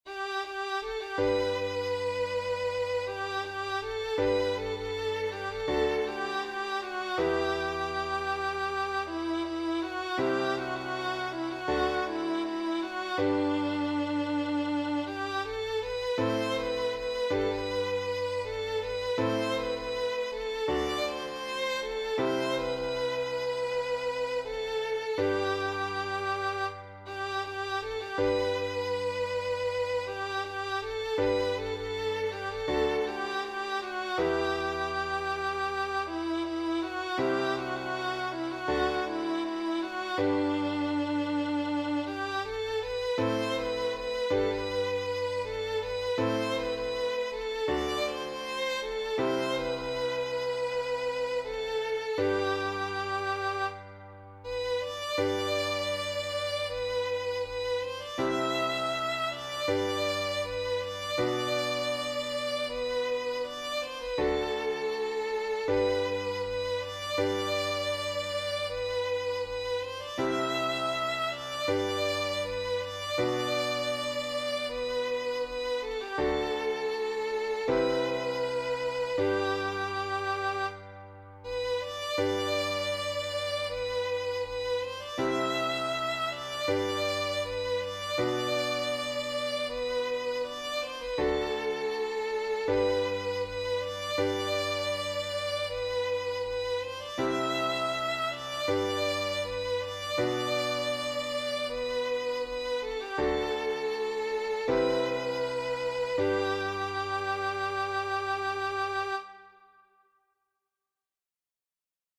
contemporary worship song